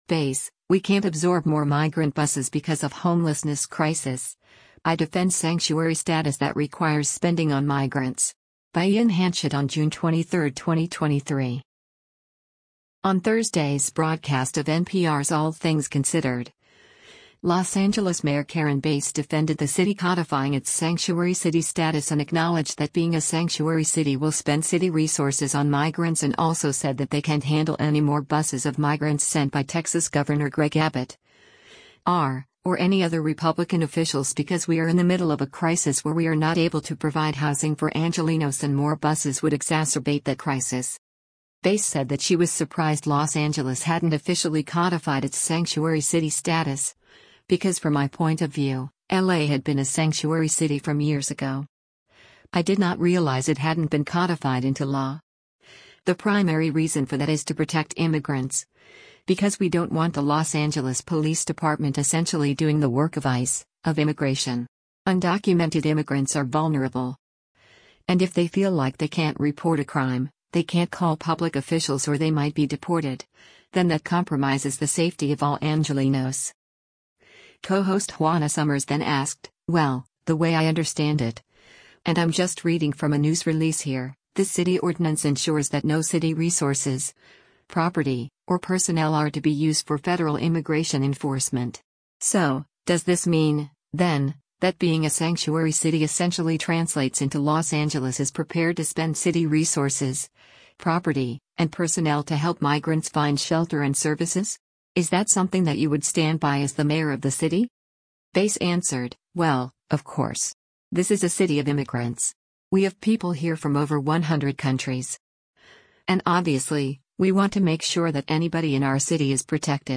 On Thursday’s broadcast of NPR’s “All Things Considered,” Los Angeles Mayor Karen Bass defended the city codifying its sanctuary city status and acknowledged that being a sanctuary city will spend city resources on migrants and also said that they can’t handle any more buses of migrants sent by Texas Gov. Greg Abbott (R) or any other Republican officials because “we are in the middle of a crisis where we are not able to provide housing for Angelenos” and more buses “would exacerbate that crisis.”